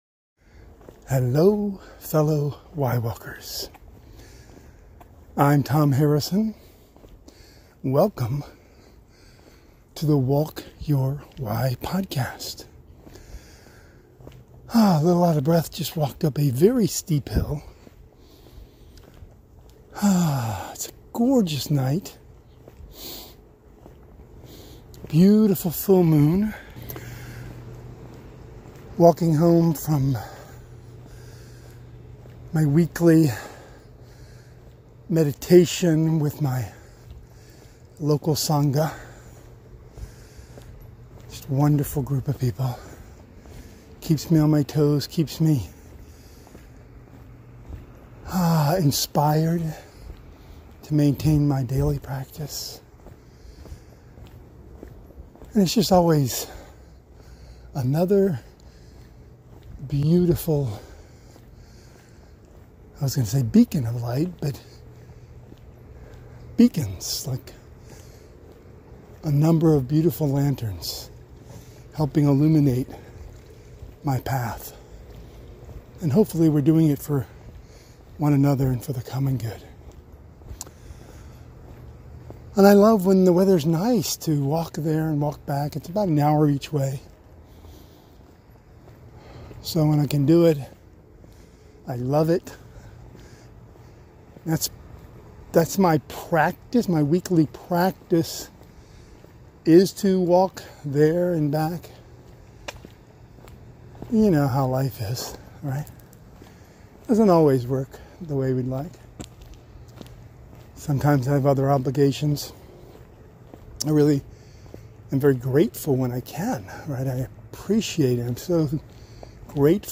Please join me, as I walk (and talk) my way home from my weekly meditation group along backroads and trails. Every step a moonlit step of joyful gratitude.